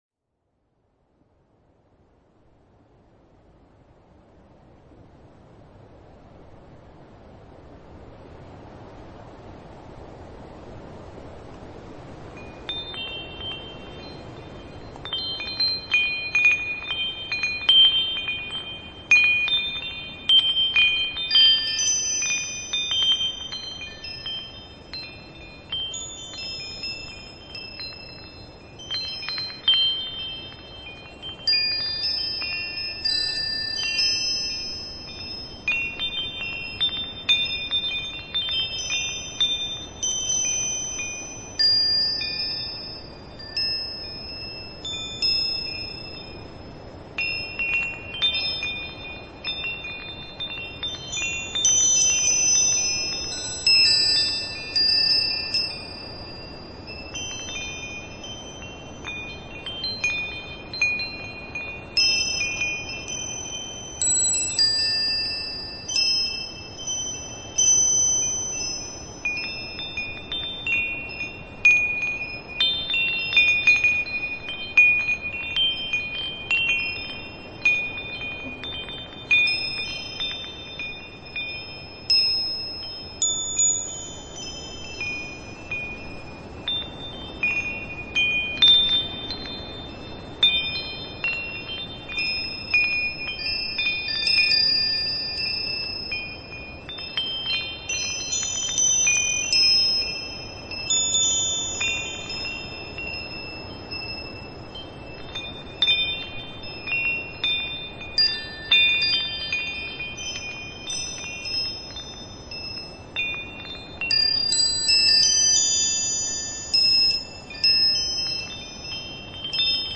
nature sounds and atmospheric sounds, perfect for relaxation
new age
ambient
LoFi Mp3